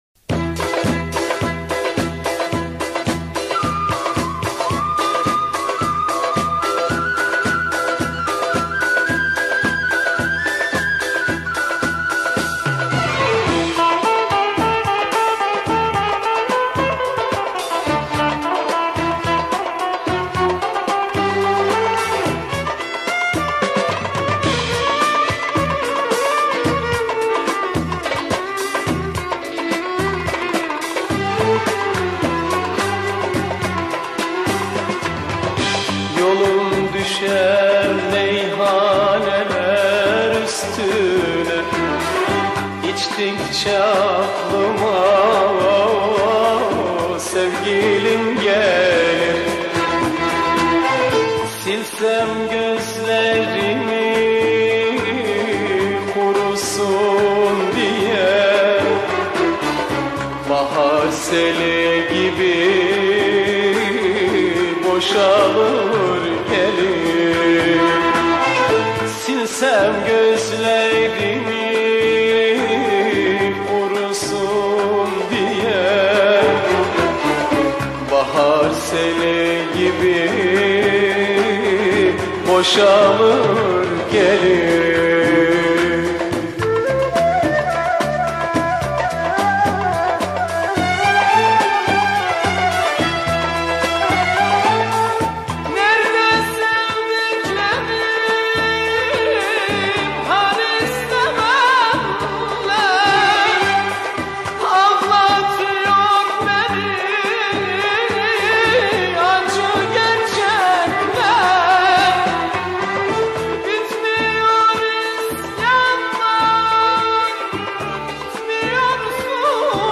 Arabesque, Turkish Folk Music